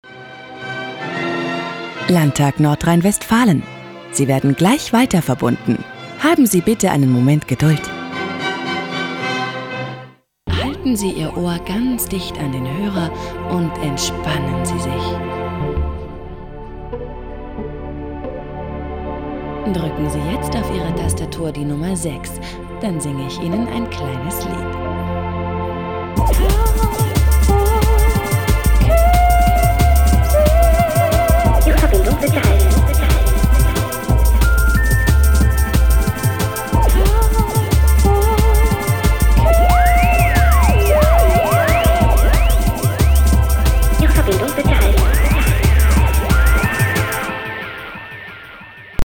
deutsche Profi Sprecherin mit einer vielseitigen Stimme: freundlich warm - szenemässig cool - sinnlich lasziv . Spezialität: Dialekte und Akzente
Sprechprobe: eLearning (Muttersprache):